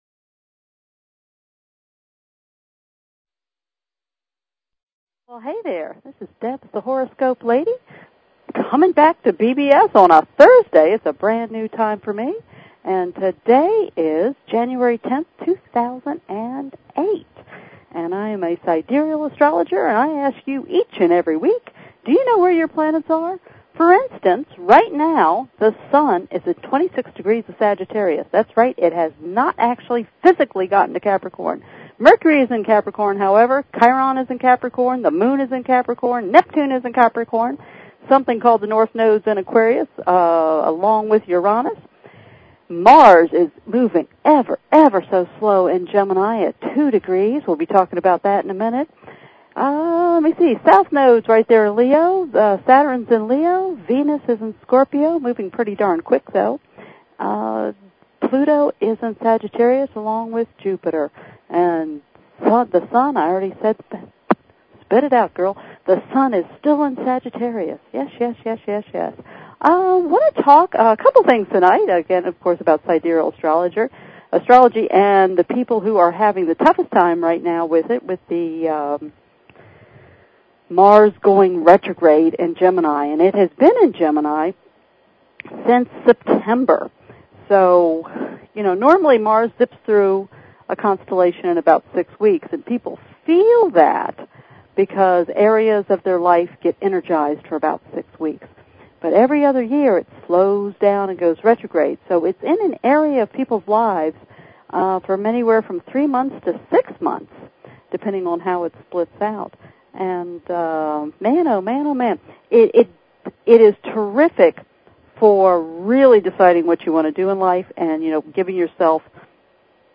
Talk Show Episode
Her guests include other leading sidereal astrologers and the occasional celebrity who has found sidereal astrology useful.